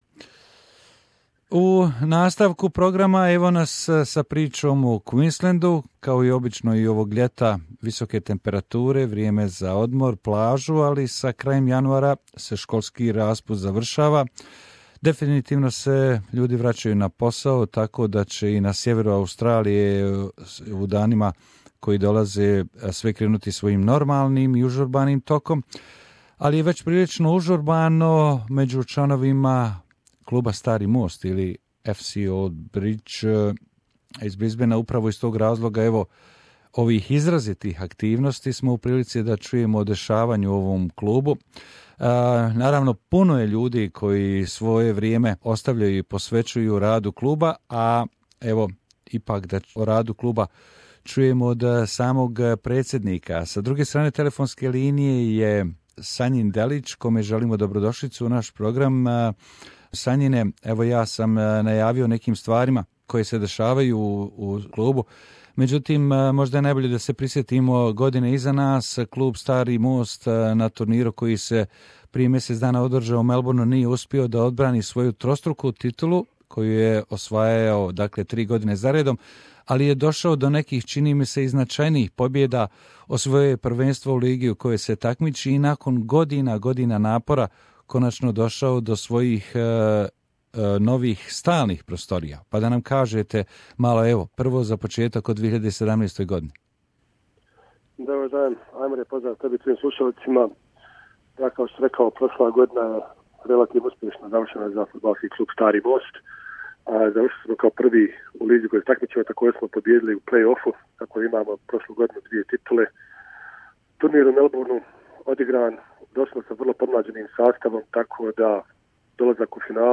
U razgovoru za naš radio program